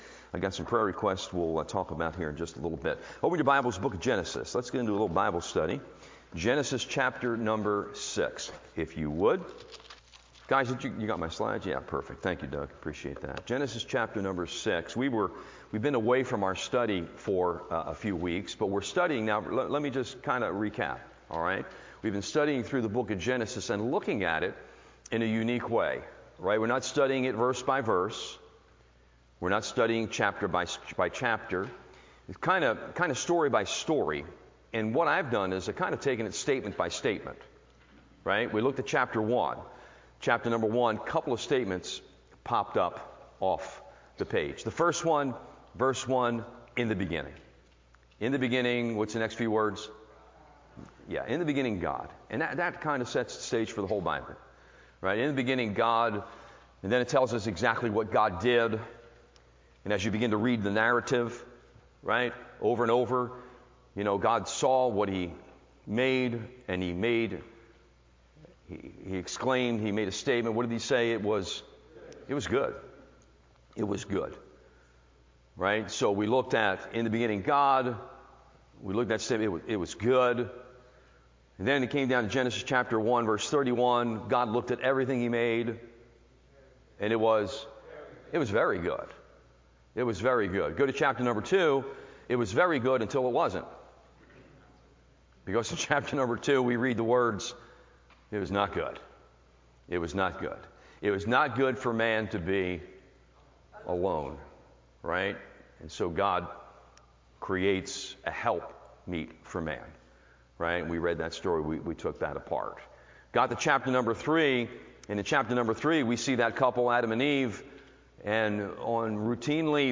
Genesis Series Wednesday Night Bible Study